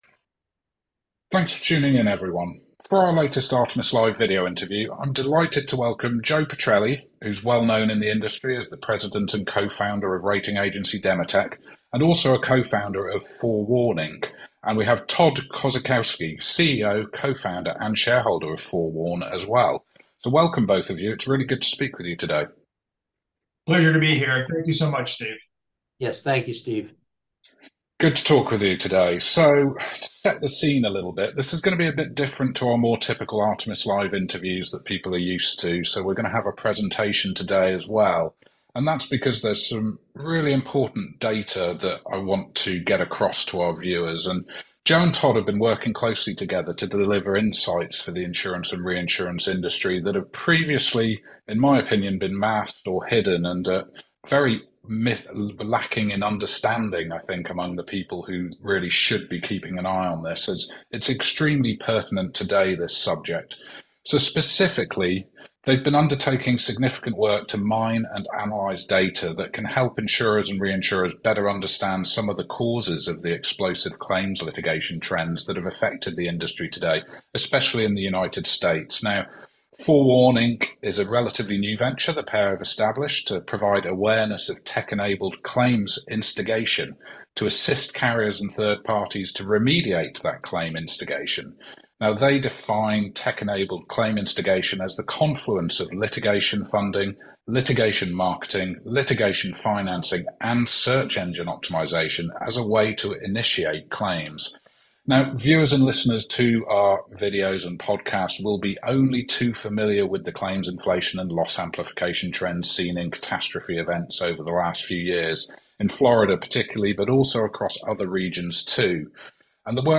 This podcast episode features an interview with 4Warn Inc., a startup focused on analysing digital data related to insurance claims instigation, a practice that has been driving a significant elevation in claims frequency through to insurers, with ramifications for reinsurance and ILS market interests.